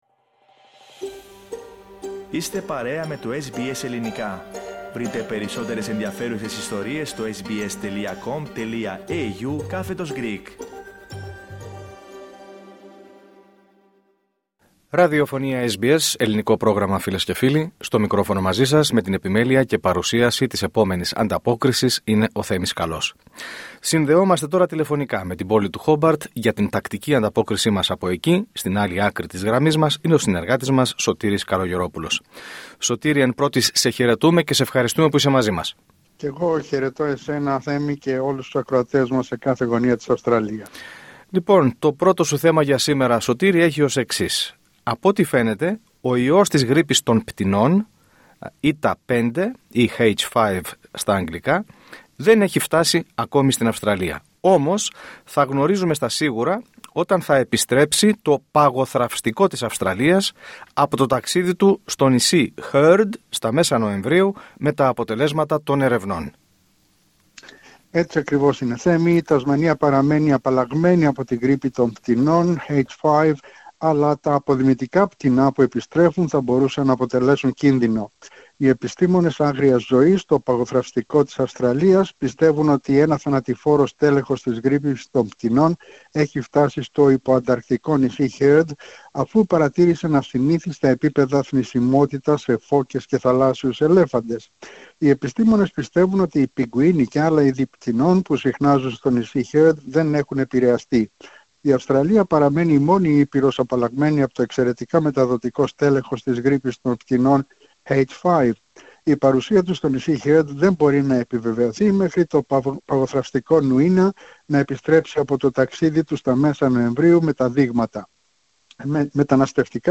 Ανταπόκριση-Τασμανία: Απαλλαγμένη από την γρίπη των πτηνών παραμένει η Τασμανία